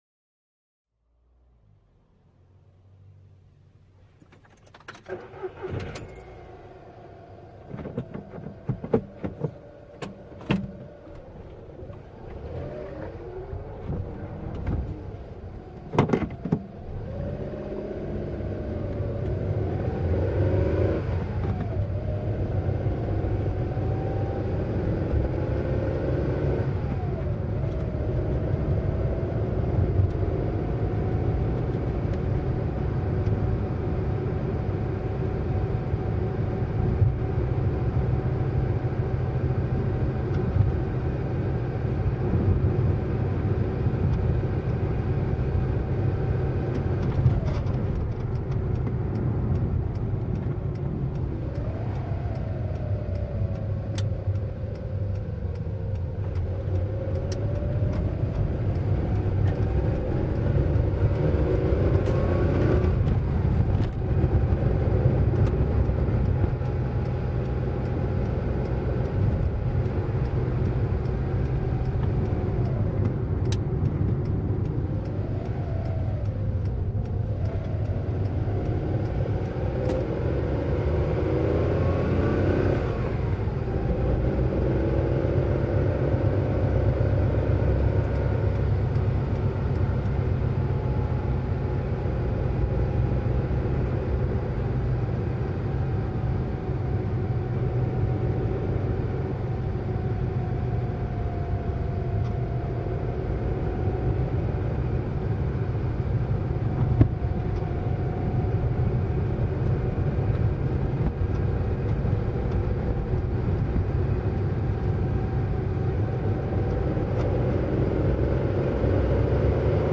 دانلود آهنگ رانندگی در ماشین 3 از افکت صوتی حمل و نقل